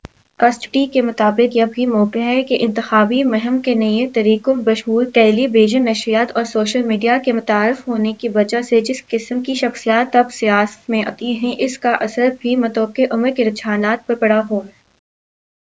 deepfake_detection_dataset_urdu / Spoofed_TTS /Speaker_16 /107.wav